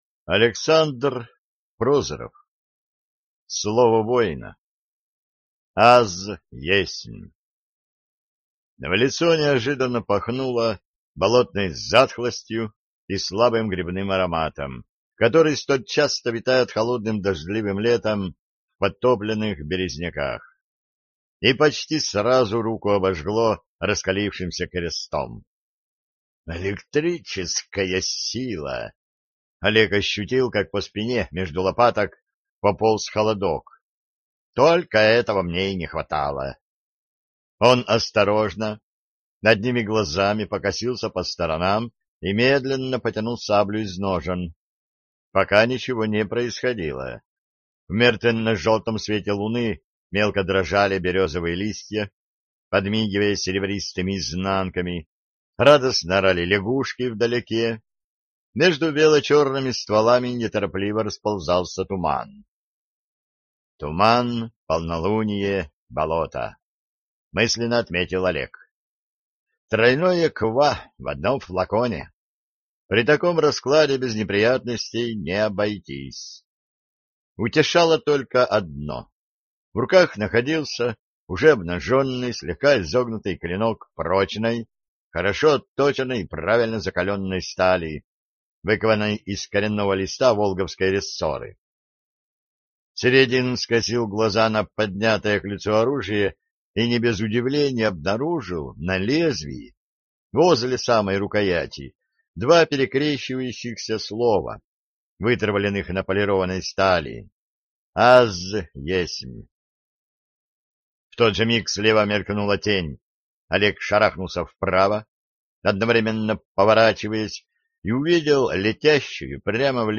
Аудиокнига Слово воина | Библиотека аудиокниг